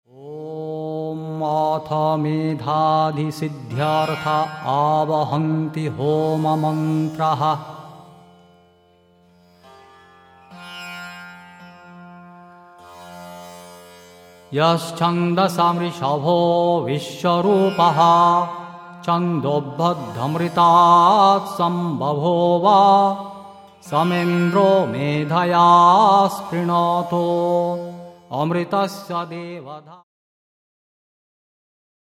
(Vedic mantras in chant and song)